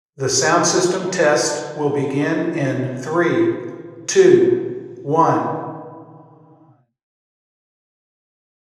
Microphone: Sennheiser Ambeo
This large shoebox has open-plan seating.
The HVAC is very noisy. Acoustic testing would be nearly impossible if not for the 14-second log sweep method used by GratisVolver. Here are the measures collected 34 ft from an omni source.